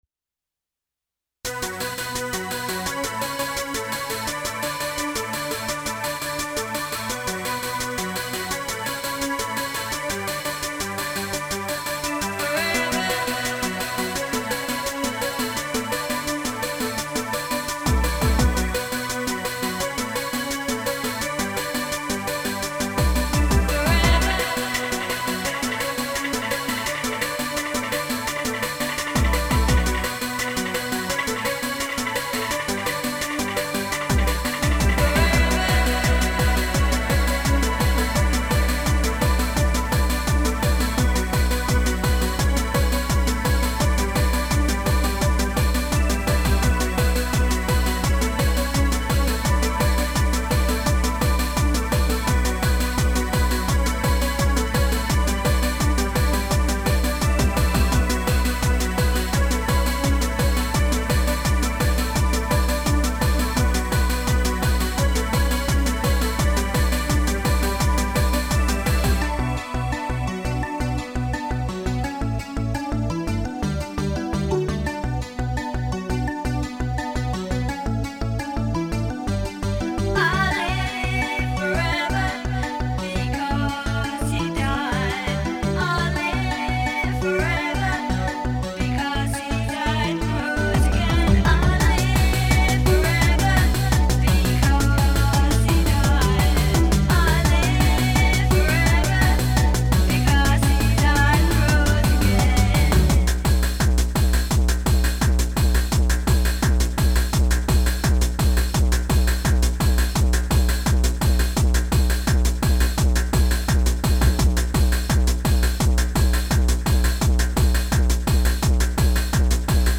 I wrote the lyrics and the music, and she sang.
I’m particularly proud of the bass line-so crank it up…